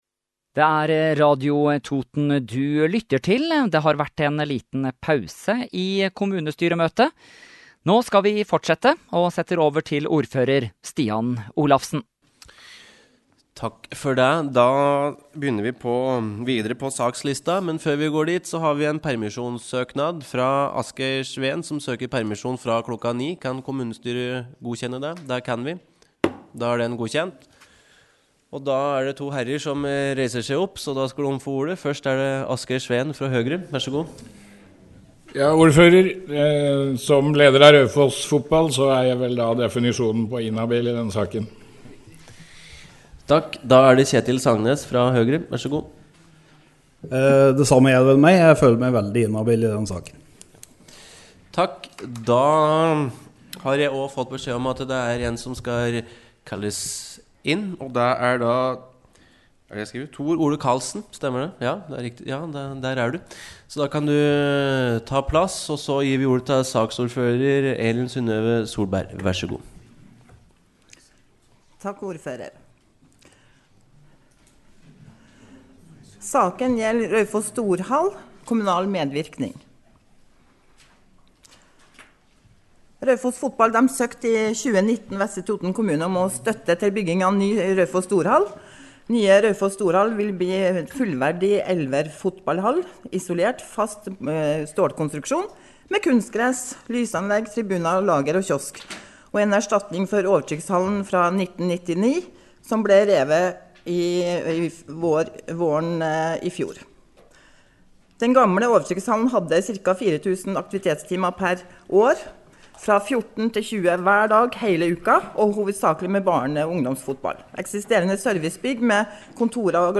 Kommunestyremøte fra Vestre Toten 5. mars kl. 18:00- 18:02
Kommunestyresalen Møteinnkalling